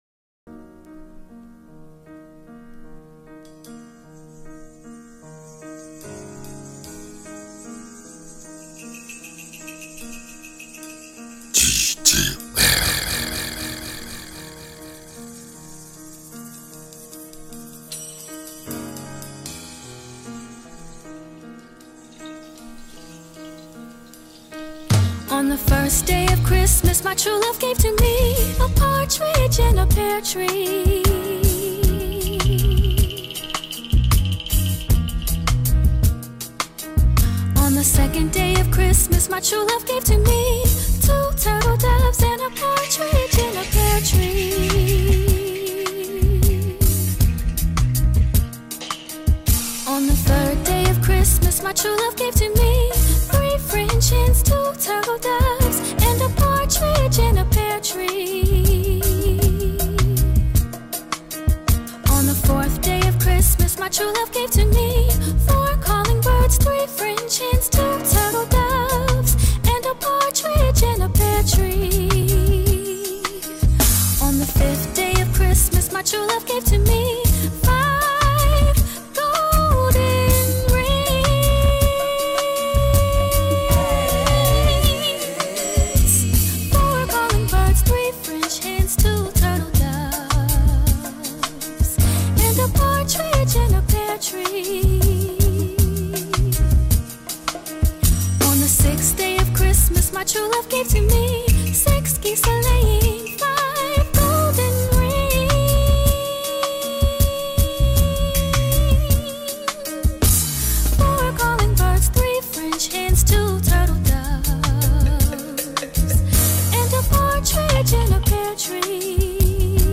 A cosy and confy groove moment !